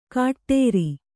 ♪ kāṭṭēri